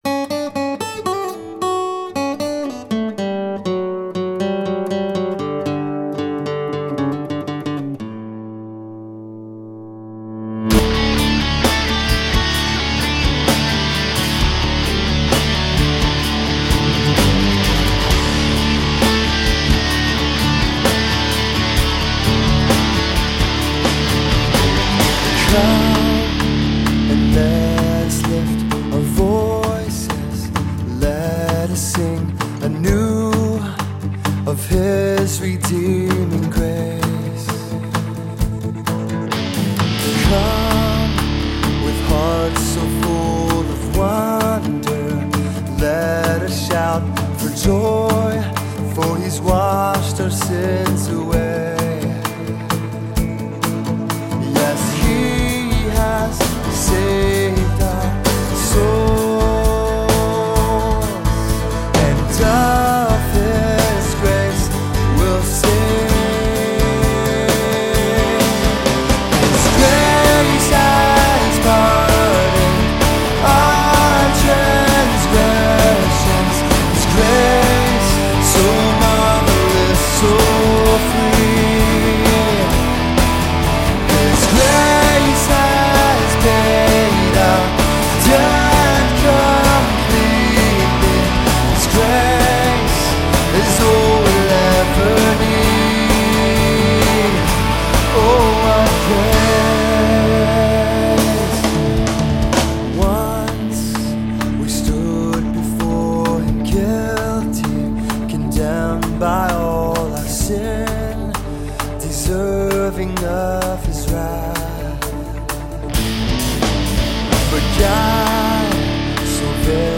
upbeat, rock-style song